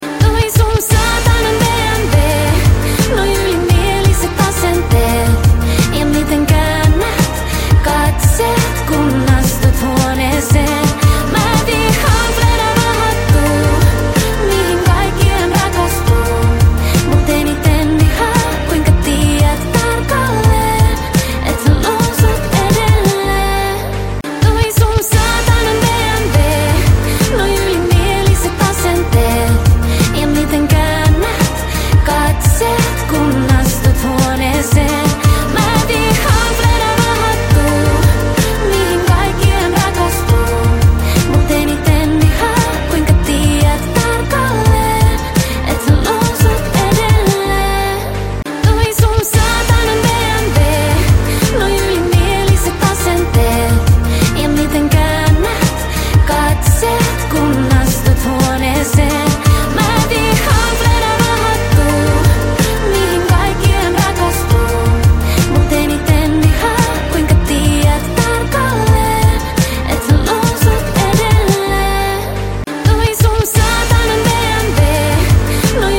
Наслаждайтесь энергичным треком в любое время!